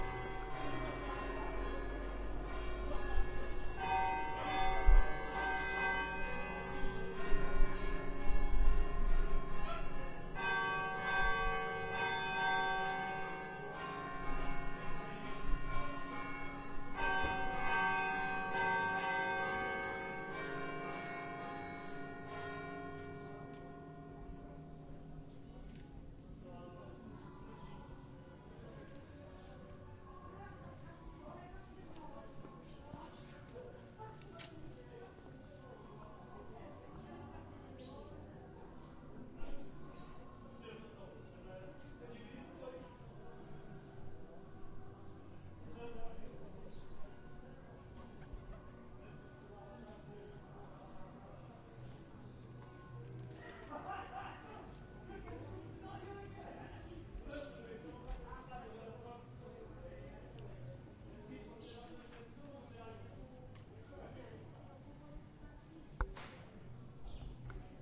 描述：那声音提供了里斯本的教堂之一。
Tag: 教堂 城市